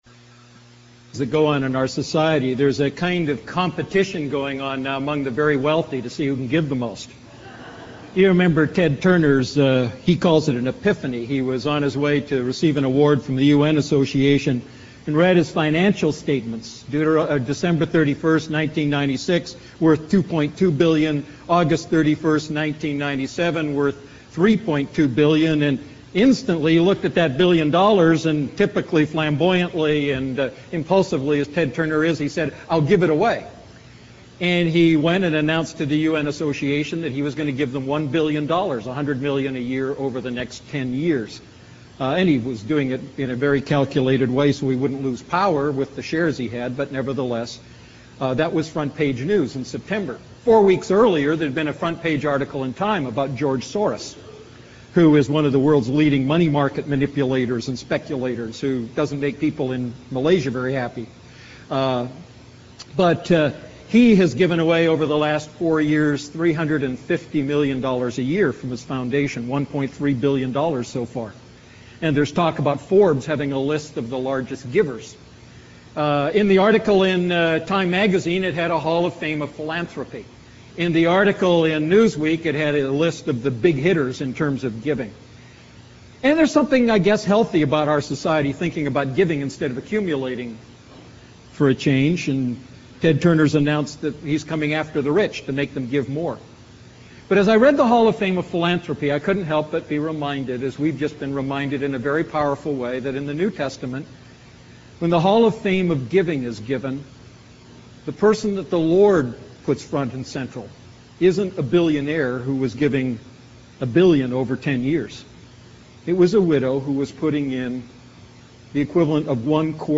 A message from the series "Prayer."